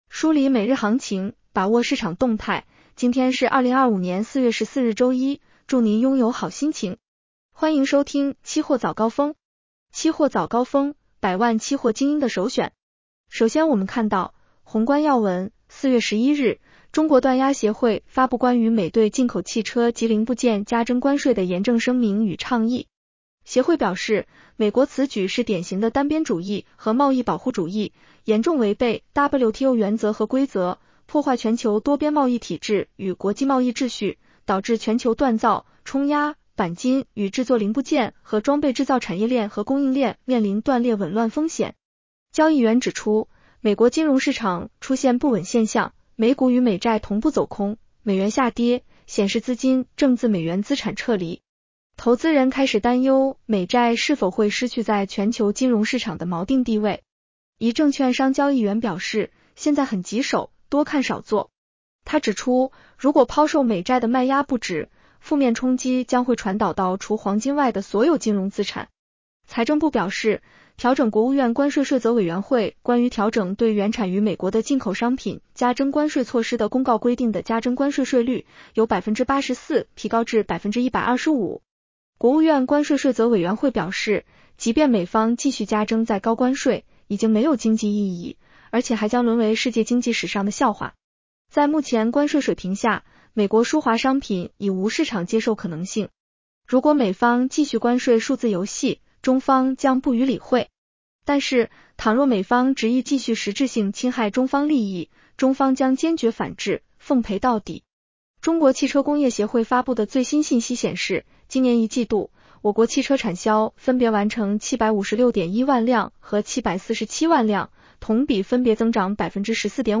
期货早高峰-音频版 女声普通话版 下载mp3 宏观要闻 1. 4月11日，中国锻压协会发布关于美对进口汽车及零部件加征关税的严正声明与倡议。